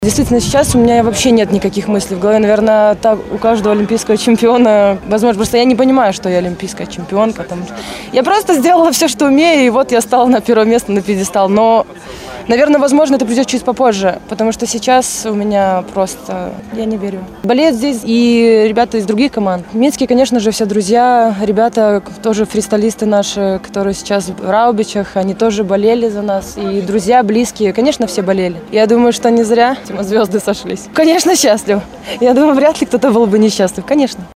Першыя ўражанні Алімпійскай чэмпіёнкі